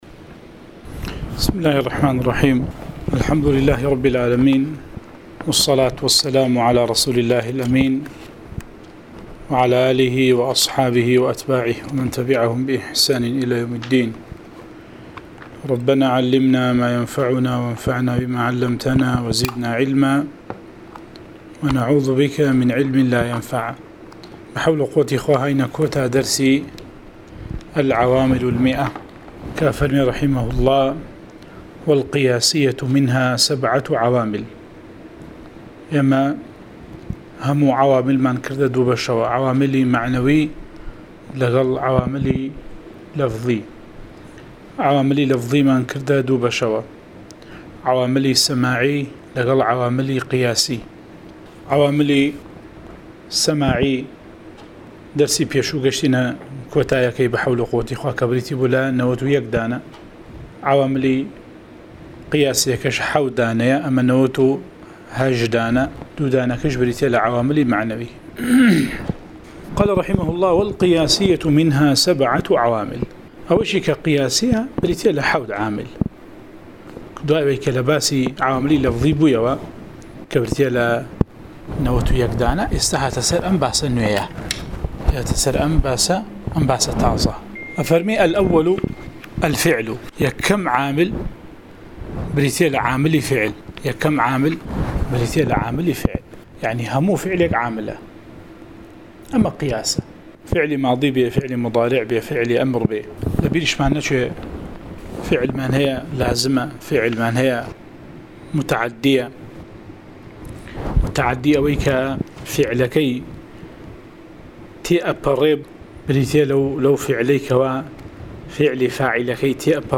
13 ـ شەرحی العوامل المائة، (عوامل الجرجانی) (نوێ) وانەی دەنگی: - شرح عوامل المائة (عوامل الجرجاني)